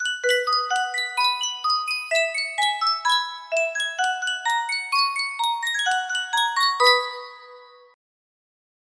Sankyo Miniature Music Box - Wabash Cannonball BBR music box melody
Full range 60